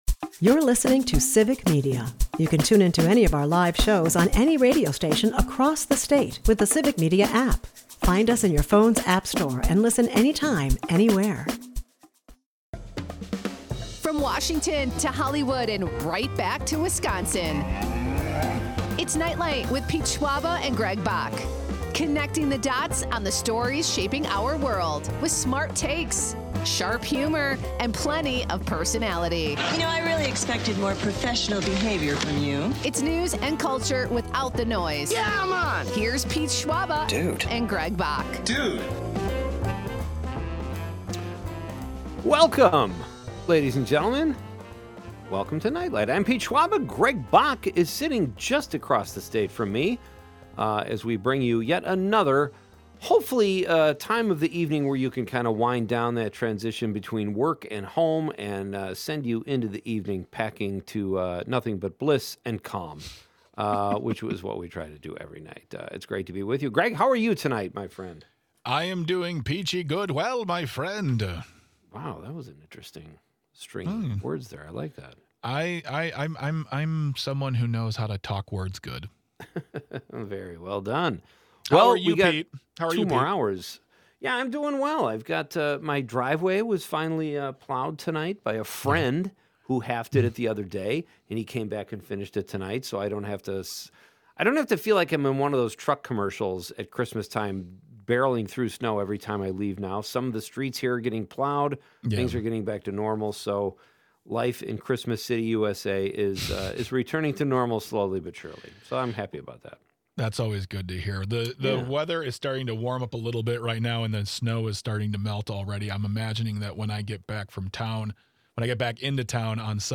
Guests: Hooman Madj